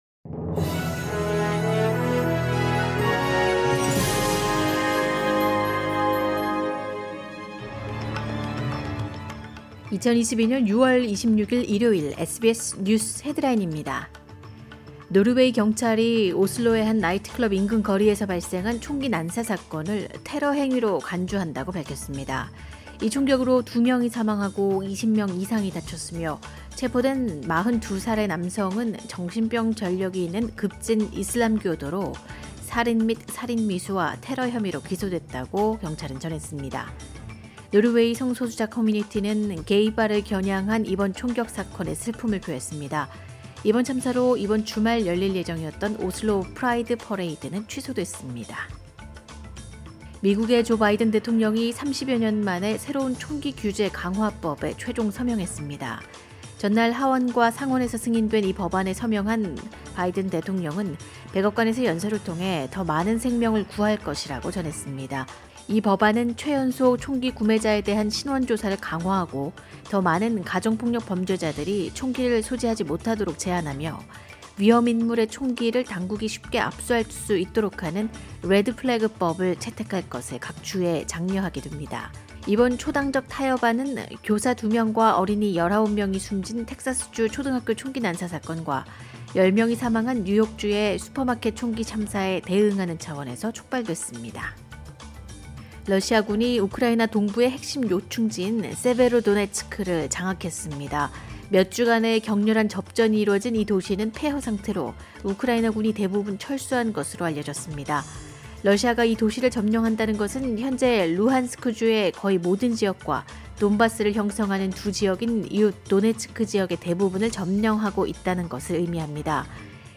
2022년 6월 26일 일요일 SBS 한국어 간추린 주요 뉴스입니다.